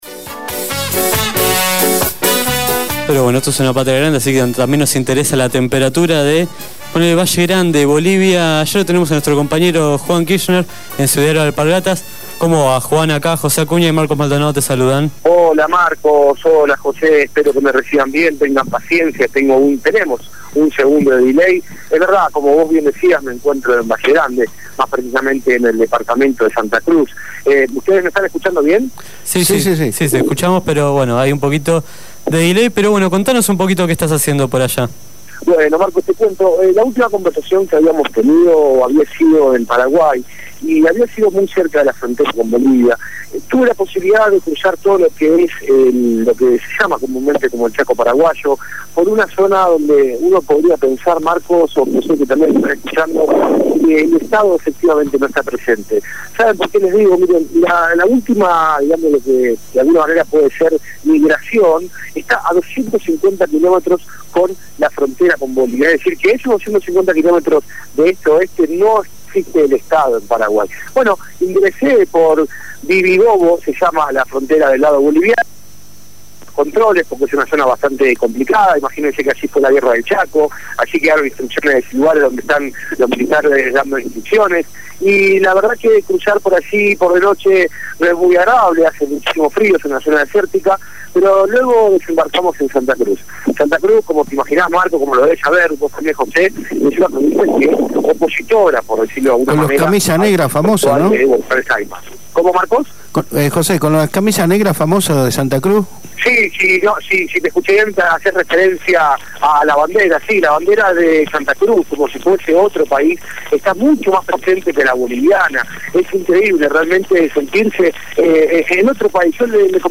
Llanio González, Consul General de Cuba, habló en Abramos la Boca.